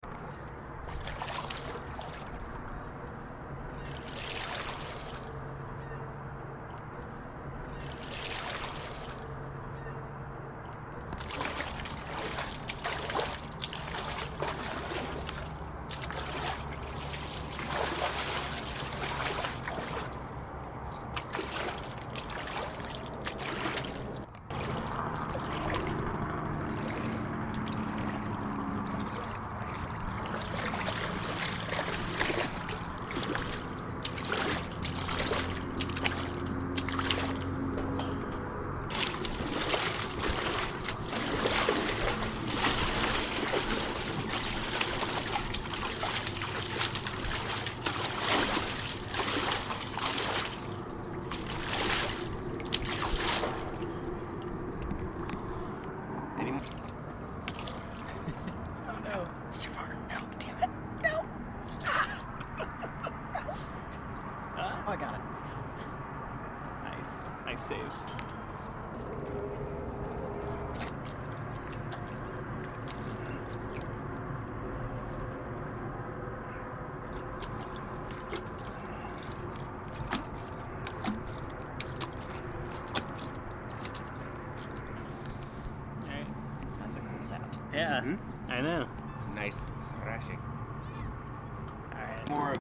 • sinking to the bottom of the sea: long, sweeping motions with the tree branch through the water; some dragging the floating driftwood along
• bobbing in the water: push the driftwood down, just beneath the surface with the branch and let it pop back up–it makes a cute little “ploop” noise
• Seahorse swimming: quick, light swirling of the water with a long thin stick
Compilation of the variety of water sounds we recorded to incorporate into the show:
splashes-compilation-2.mp3